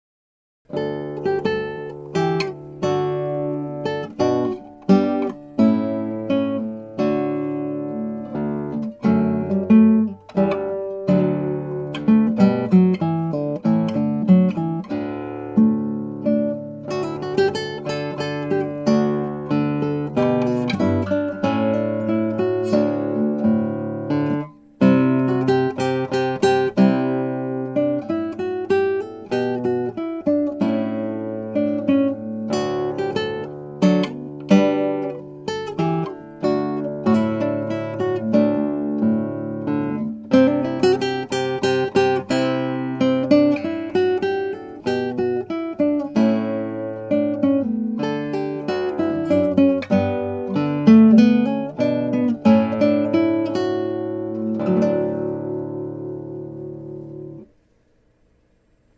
galliarda_6pieces_lute.mp3